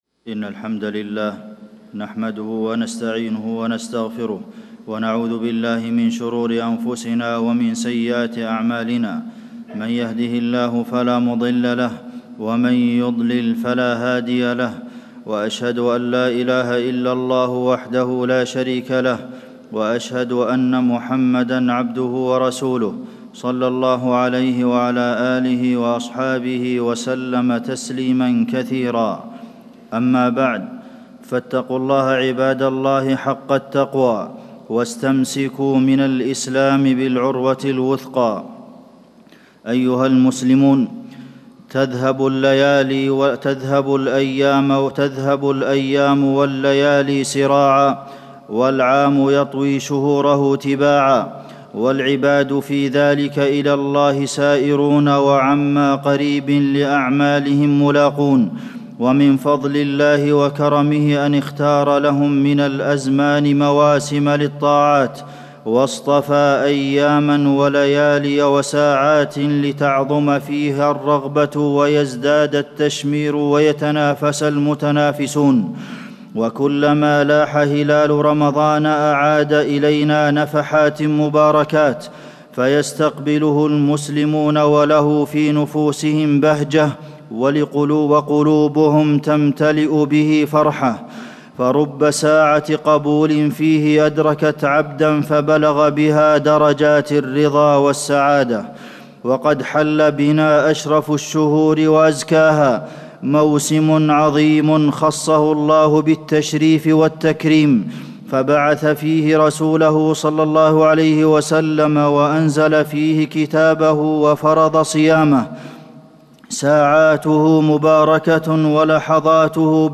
تاريخ النشر ٢ رمضان ١٤٣٩ هـ المكان: المسجد النبوي الشيخ: فضيلة الشيخ د. عبدالمحسن بن محمد القاسم فضيلة الشيخ د. عبدالمحسن بن محمد القاسم استقبال رمضان واغتنامه